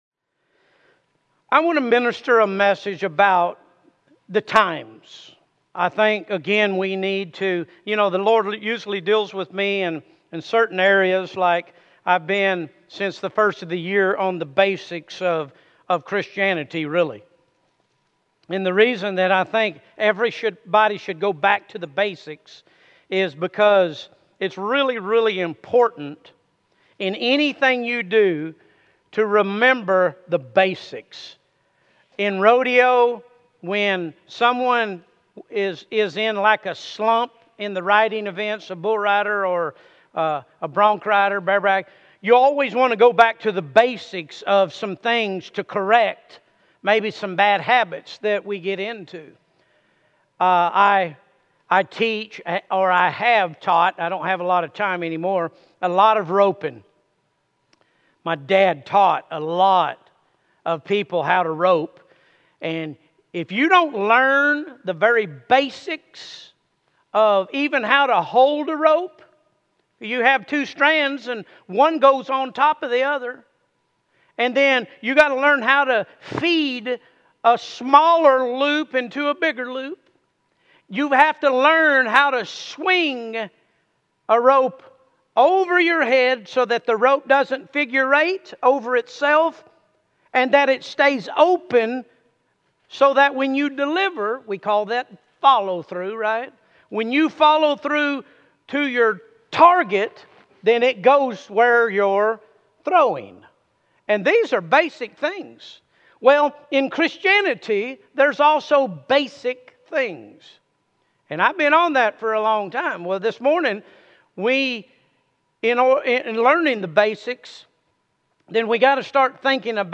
Sermons ⋆ Williamson County Cowboy Church - Liberty Hill, TX